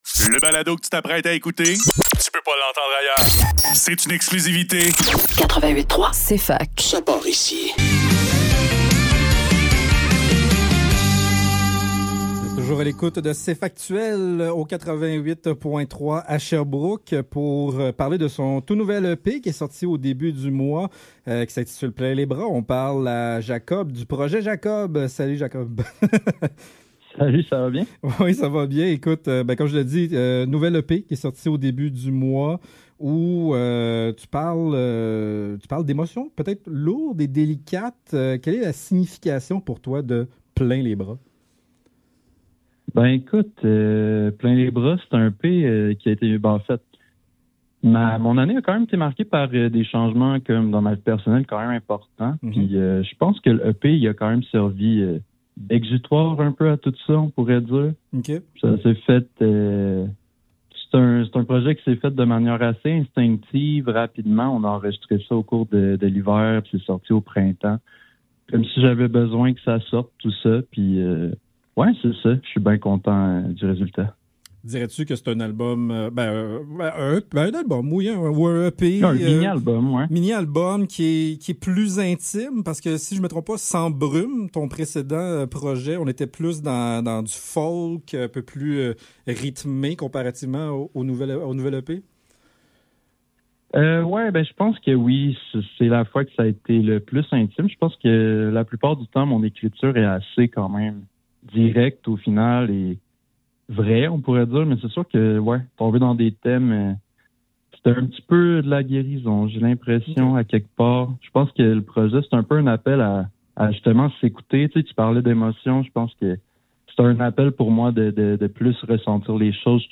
Cfaktuel Cfaktuel - Entrevue : Projet Jacob - 22 Mai 2025 May 23 2025 | 00:13:07 1x 00:00 / 00:13:07 Subscribe Share RSS Feed Share Link Embed